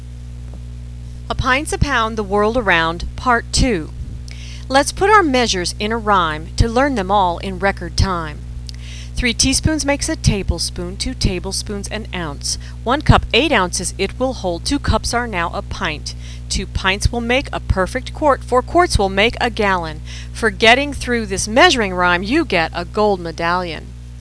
Ditty A Pints a Pound Part Two
First, my audio recorder only permits a 60-second recording.